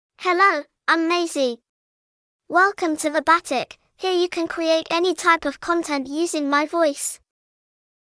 Maisie — Female English (United Kingdom) AI Voice | TTS, Voice Cloning & Video | Verbatik AI
Maisie is a female AI voice for English (United Kingdom).
Voice sample
Female
Maisie delivers clear pronunciation with authentic United Kingdom English intonation, making your content sound professionally produced.